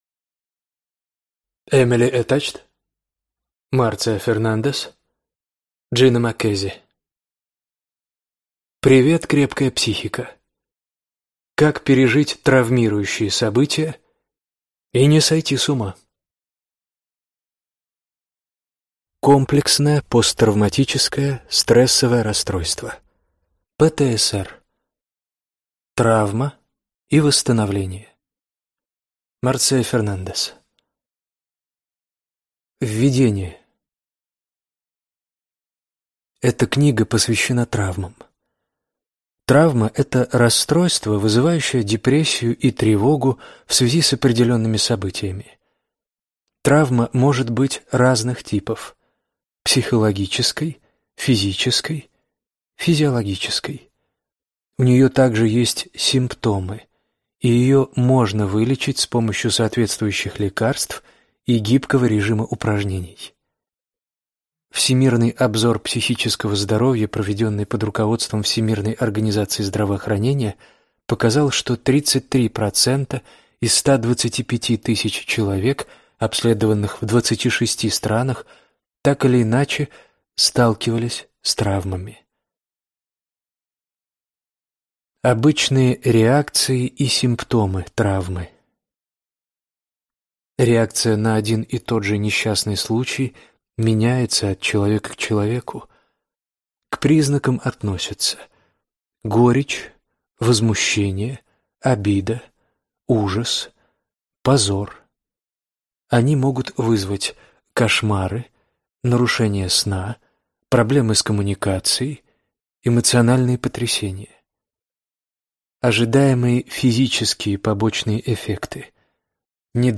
Аудиокнига Привет, крепкая психика! Как пережить травмирующие события и не сойти с ума | Библиотека аудиокниг